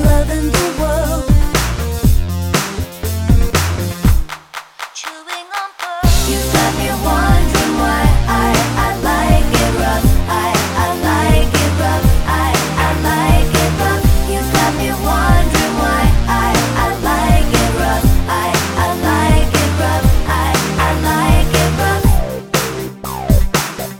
No Backing Vocals With Vocoder Pop (2010s) 3:25 Buy £1.50